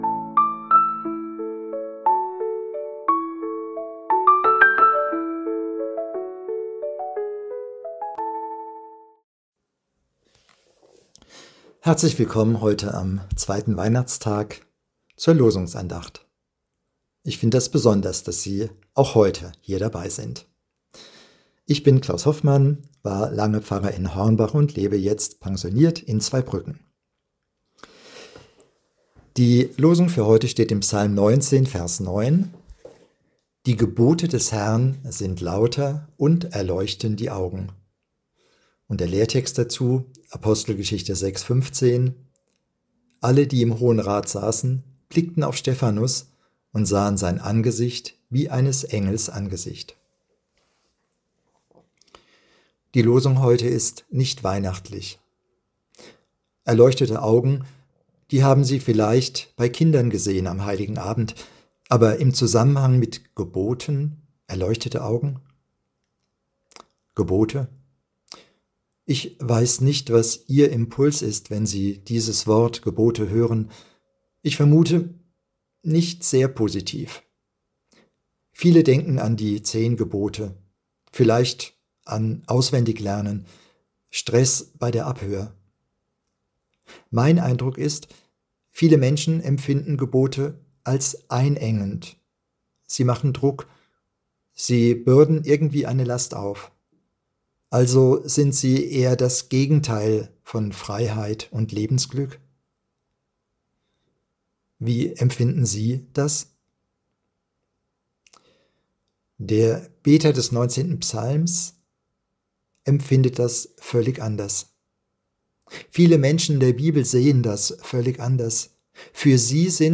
Losungsandacht für Freitag, 26.12.2025 – Prot.